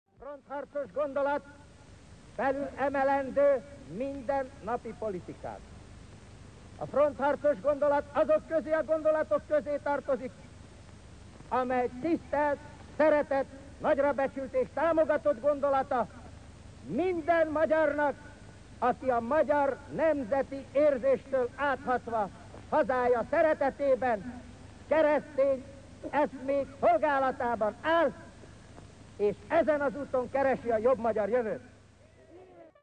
Kozma Miklós belügyminiszter beszél a Frontharcos szövetség 36os Hősök Terén megrendezett nagygyűlésén.